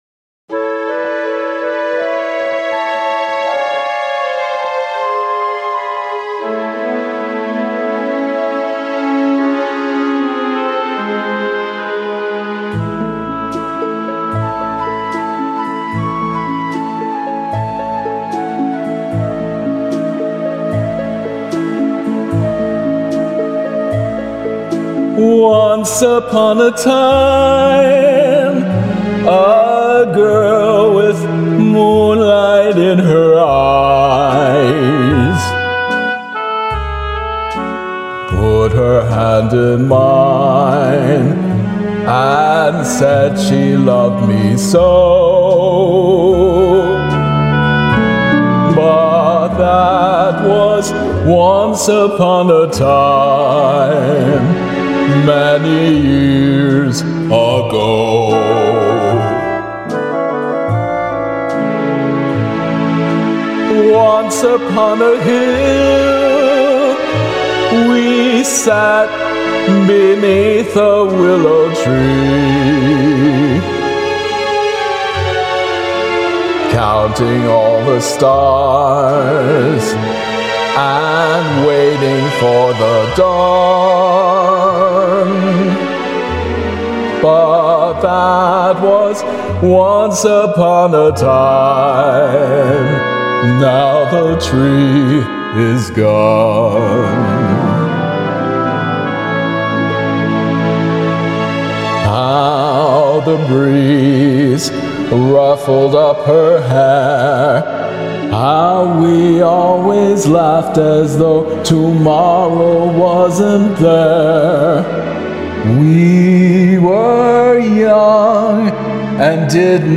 vocals
karaoke arrangement
lovely, heart-wrenching ballad